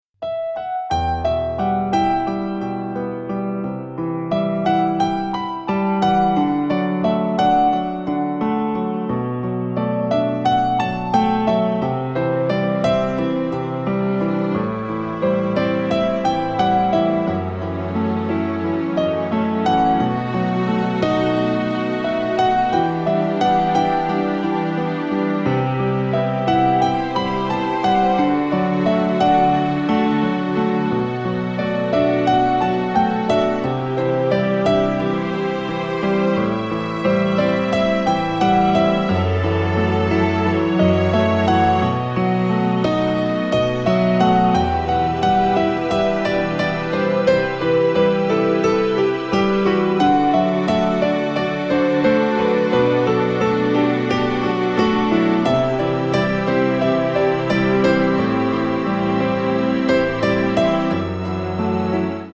• Качество: 128, Stereo
без слов
красивая мелодия
инструментальные
пианино
печальные